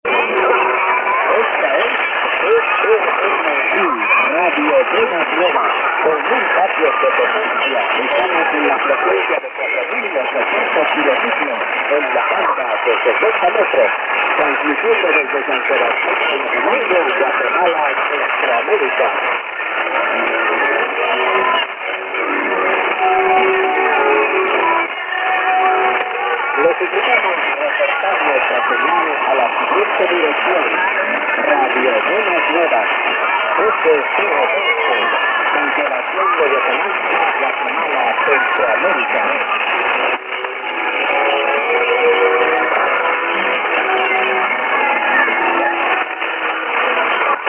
in St. John’s, Newfoundland on 01 July 1989 at 0127: